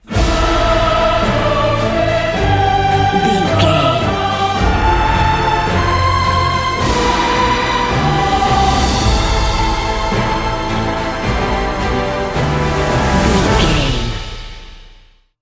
Epic / Action
Uplifting
Aeolian/Minor
energetic
powerful
brass
choir
drums
strings
trumpet